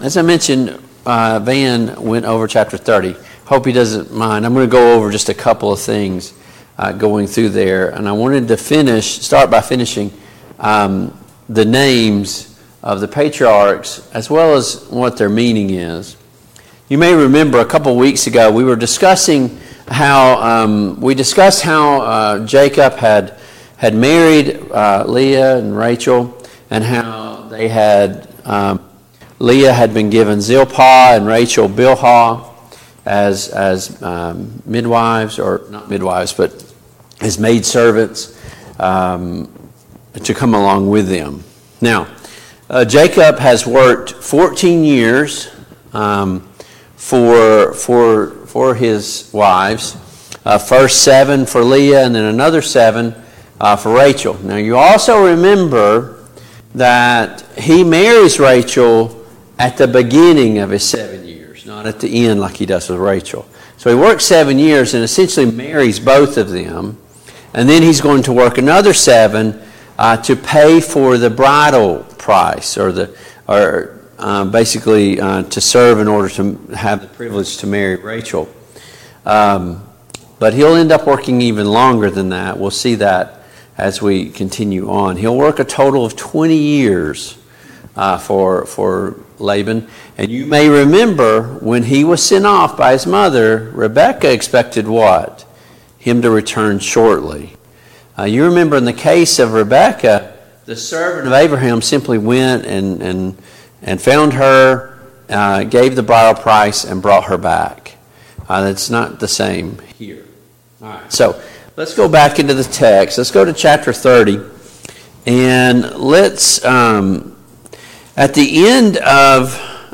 Genesis 30 Service Type: Family Bible Hour Topics: Jacob and Rachel and Leah « What do you value?